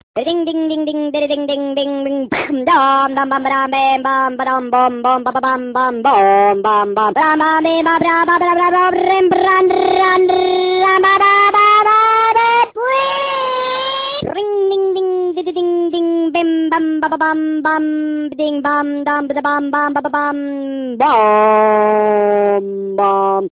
Avete mai sentito una rana che corre in auto?
rana_pazza.wav